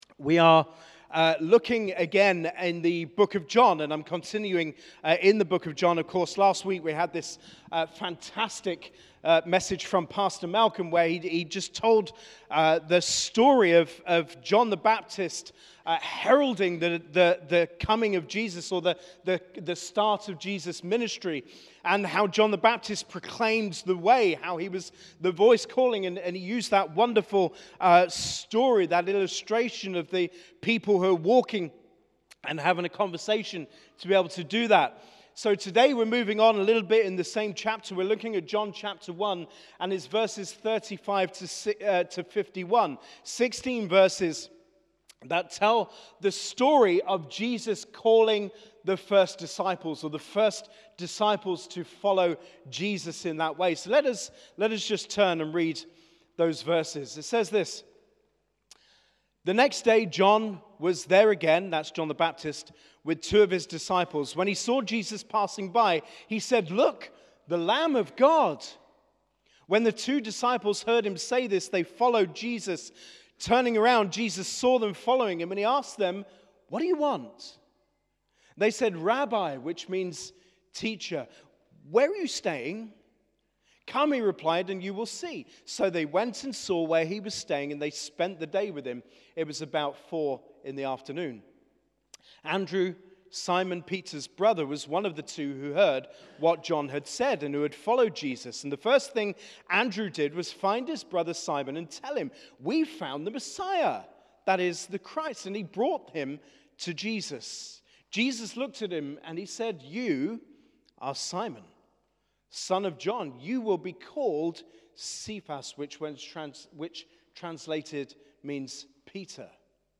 Sermon - John 1:35-51